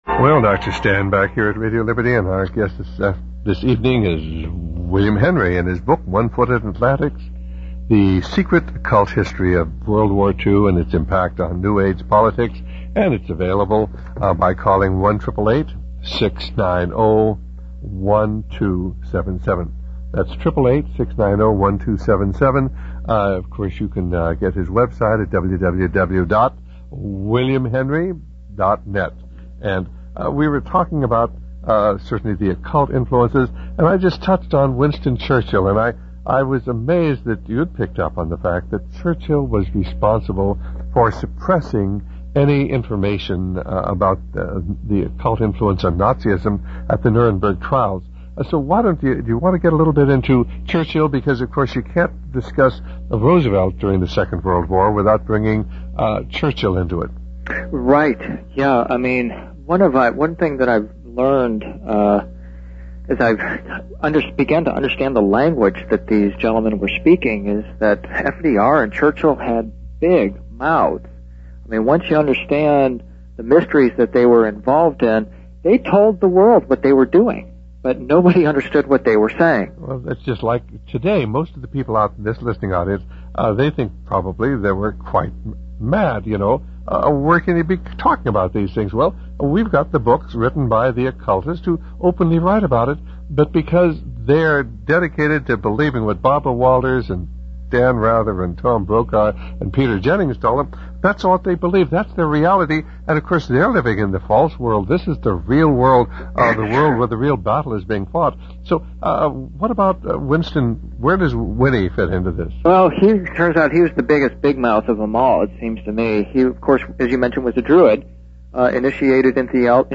In this sermon, the preacher discusses the importance of following the word of God for our own protection and ultimate satisfaction. He acknowledges that these ideas are increasingly rejected by society today.